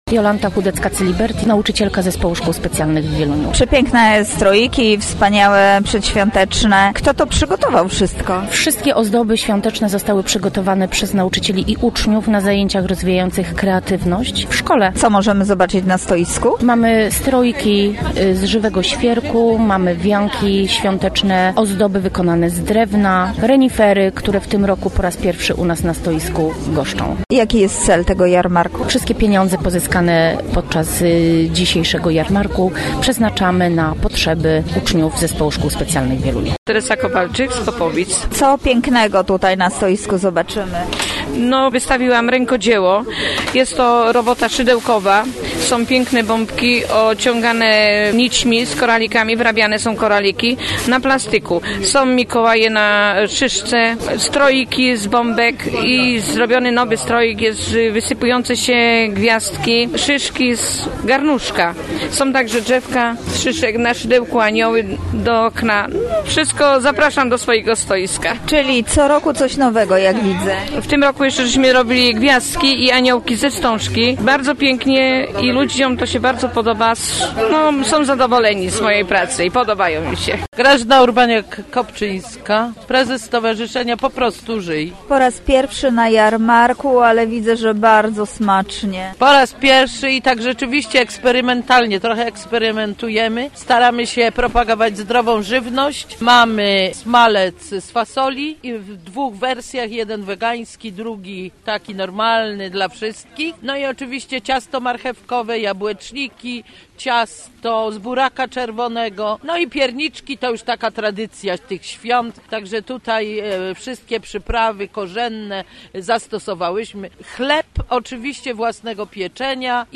Jarmark Bożonarodzeniowy w Wieluniu za nami (zdjęcia)